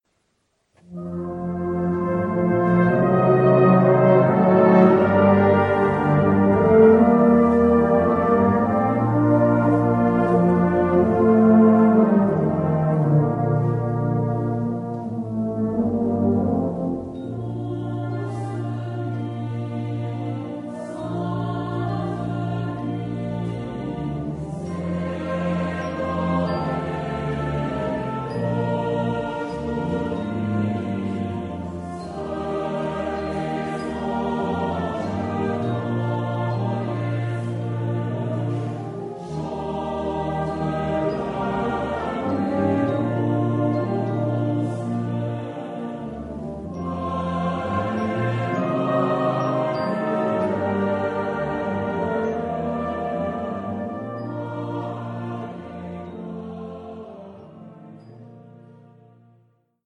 Drumset, Percussion 1, Percussion 2
Ensemble 6-7-8 voix Flex
Christmas Music / Musique de Noël